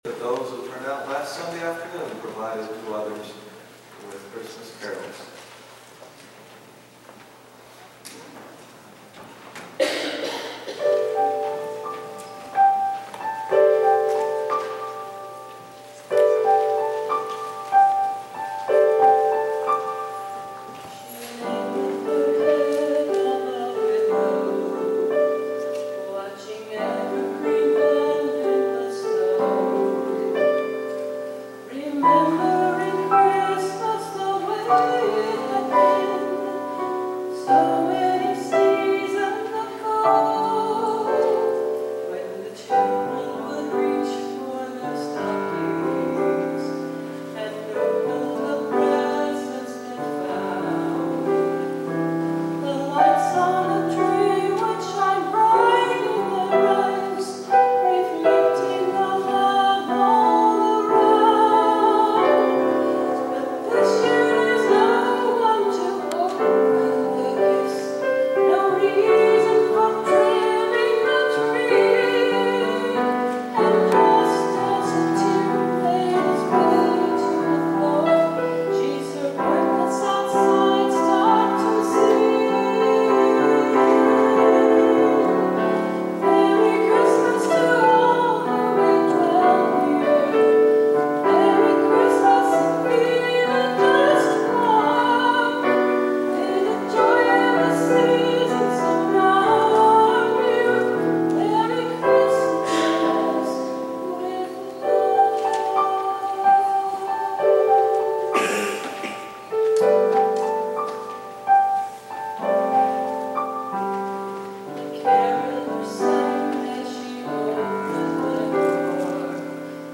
For Christmas, I choose to put together another sound bite compilation, this time of some seasonal sounds to soothe and encourage.